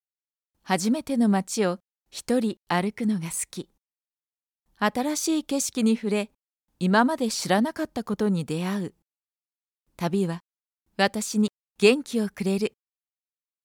声の達人女性ナレーター
落ち着いた／穏やか
やさしい
知的／クール
企業紹介／ビジネストーン
収録　　宅録
【ナレーターボイスサンプル】